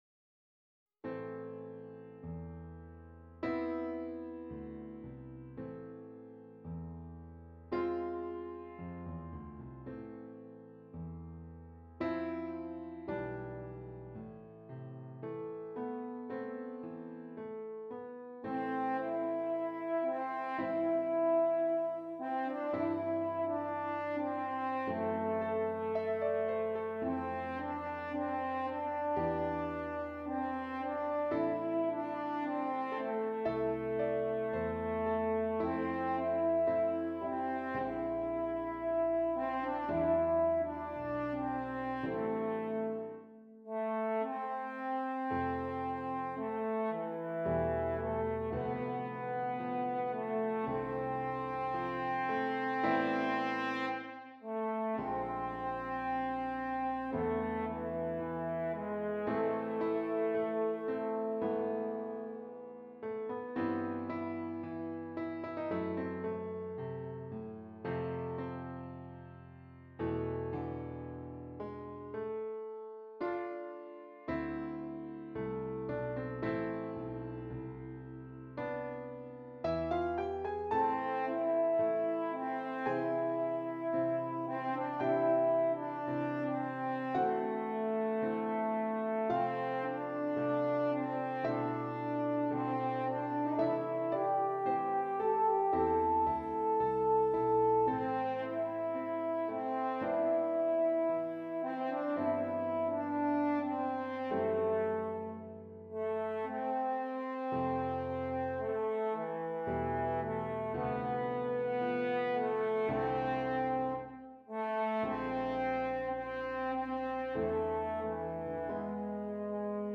F Horn and Keyboard